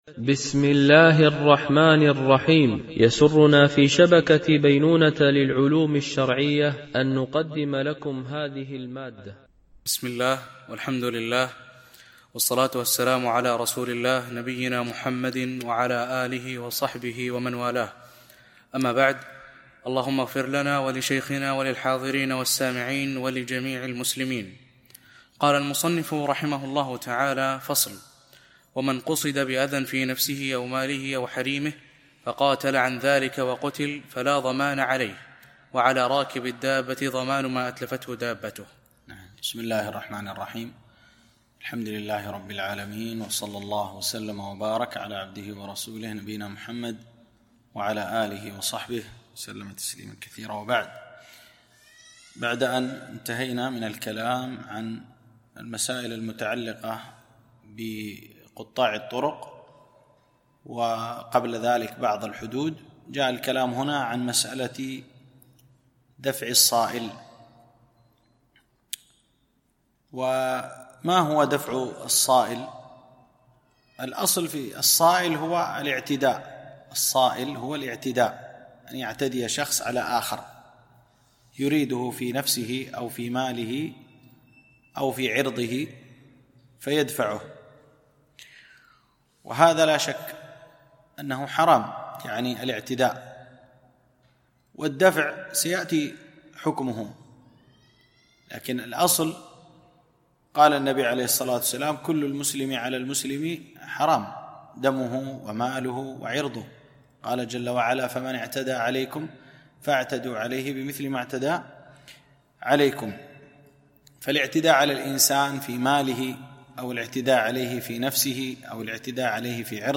شرح متن أبي شجاع في الفقه الشافعي ـ الدرس 41